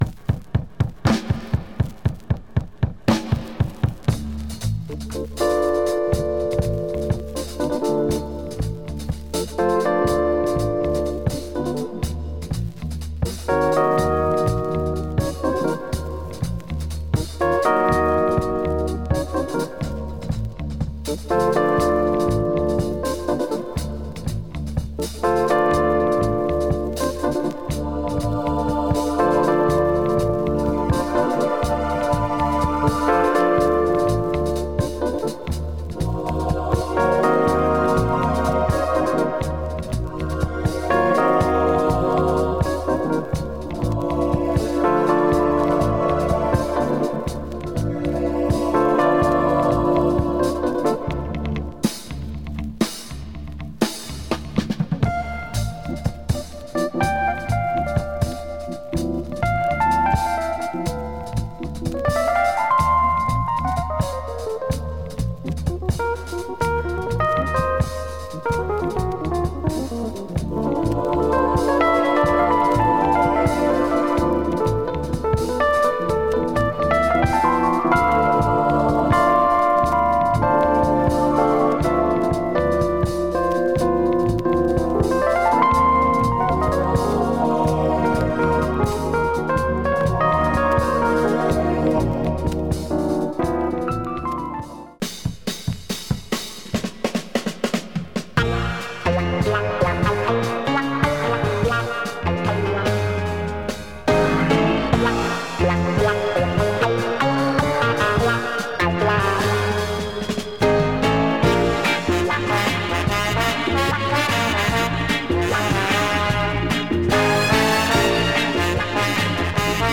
Belgian library
Same kind of sound here, groovy pop and 60's euro funk.